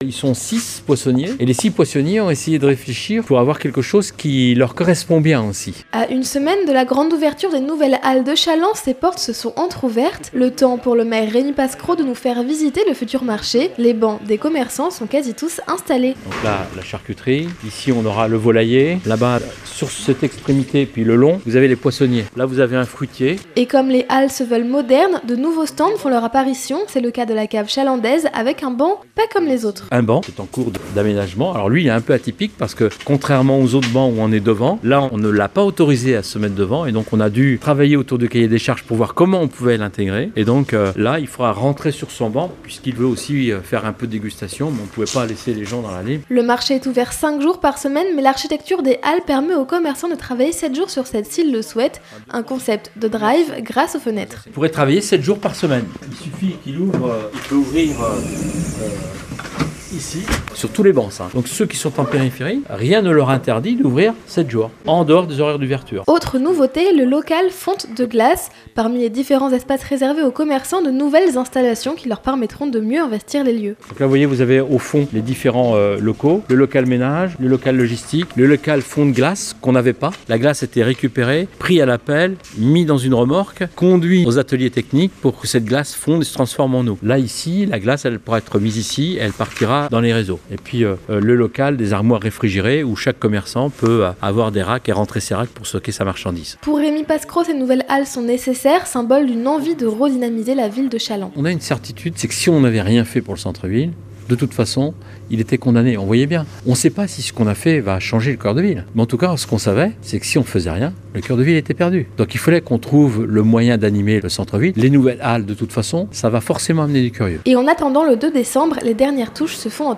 Les Reportages de NOV FM
Rémi Pascreau, maire de Challans, nous fait visiter les Halles.